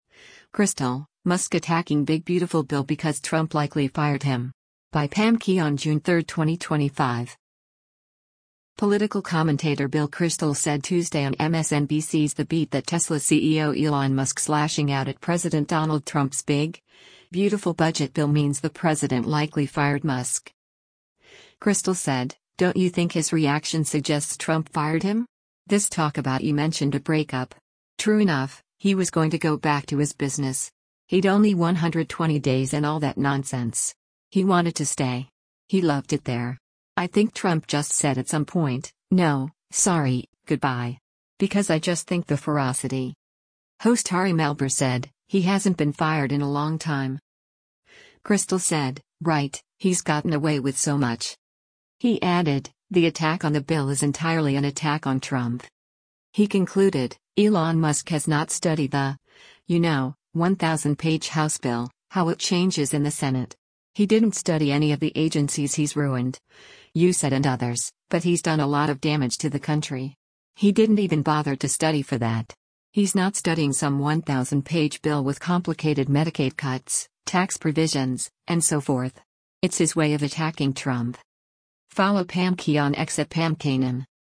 Political commentator Bill Kristol said Tuesday on MSNBC’s “The Beat” that Tesla CEO Elon Musk’s lashing out at President Donald Trump’s “Big, Beautiful” budget bill means the president likely fired Musk.
Host Ari Melber said, “He hasn’t been fired in a long time.”